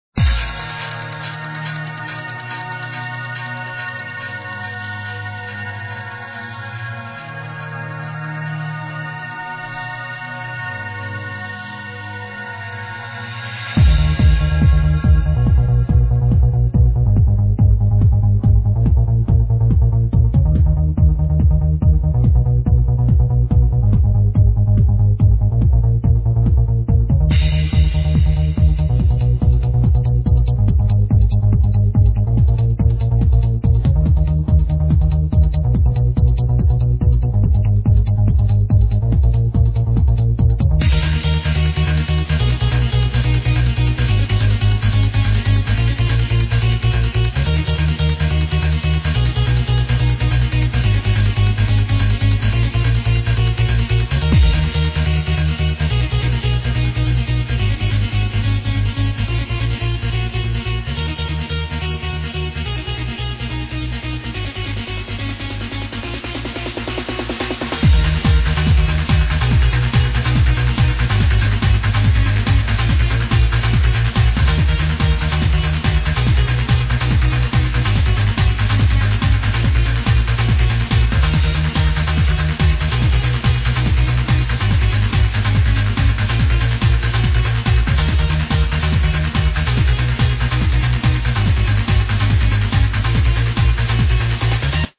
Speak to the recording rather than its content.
Edited in Cool Edit